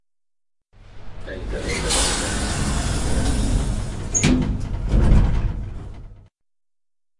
Tànger大楼的声音" 电梯关闭
描述：Tanger大厦的电梯门关闭，UPF。使用Zoom H4录制声音。